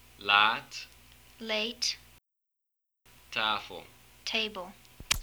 The words compared are the short version of /a/ from /tafəl/ and the long version of /a/ from /laːt/.
The vowel in this word lasts 0.308 seconds
However, this vowel only lasts 0.217 seconds, which is 0.091 seconds shorter than the other vowel.